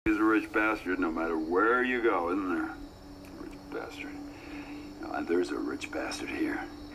He is definitely a master of vocal disguise — I believe that I would not have recognized this gruff-voiced, snarly North American guy, if I didn’t already know who it was!
Going between the two audio performances by Richard Armitage, I am struck by the vast difference in the way he pronounces the word “bastard” — in 2007 with an accent from the North of England and in 2018 with a growly North American accent.